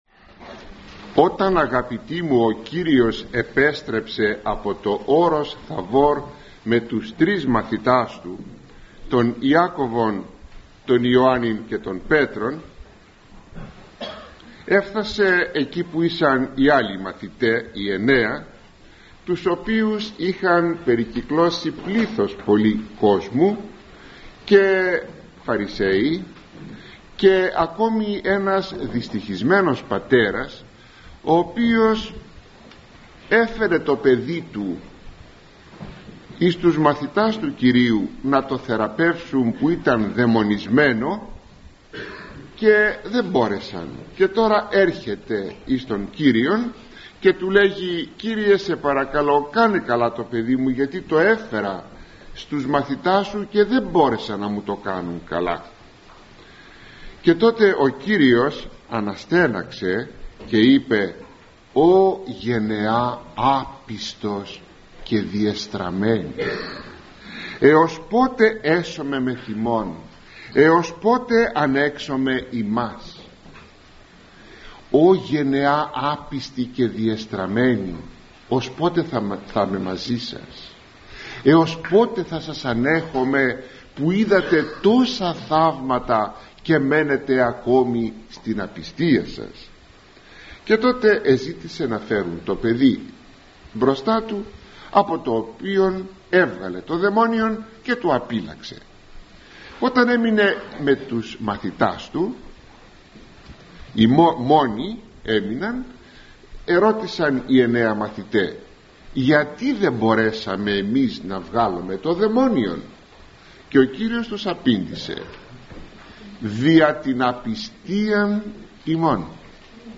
Απομαγνητοφωνημένη ομιλία